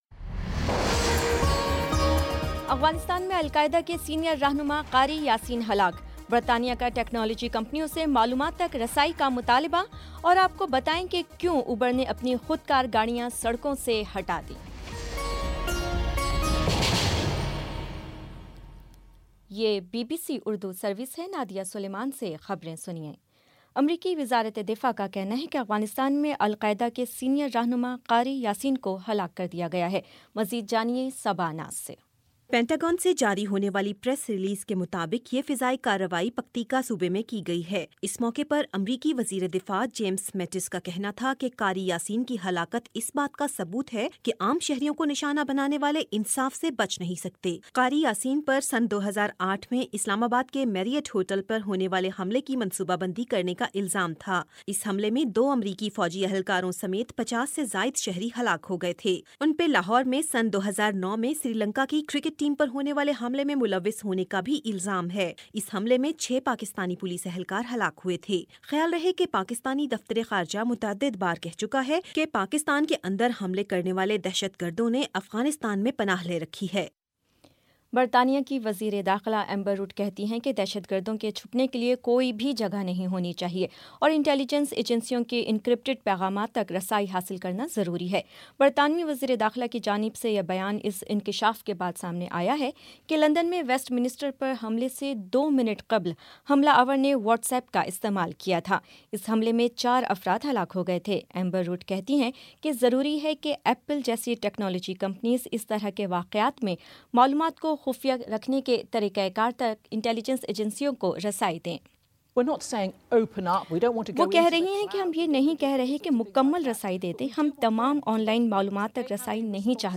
مارچ 26 : شام پانچ بجے کا نیوز بُلیٹن